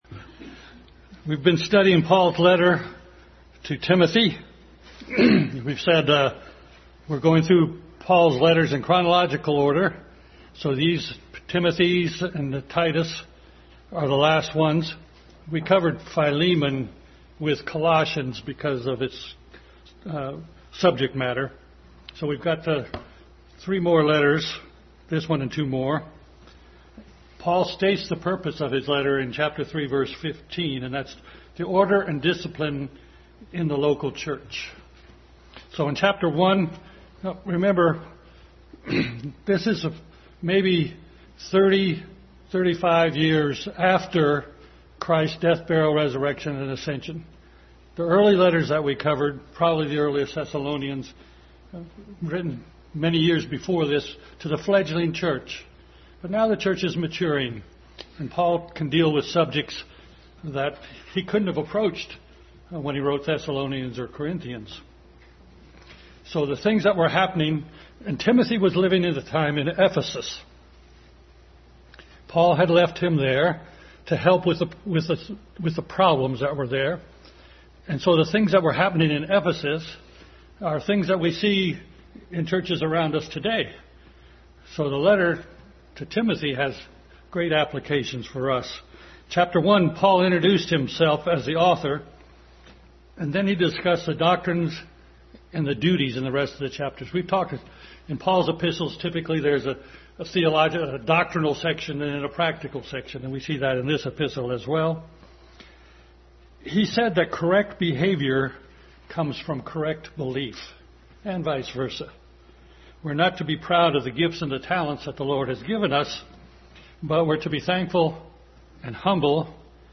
Adult Sunday School continued study in 1 Timothy.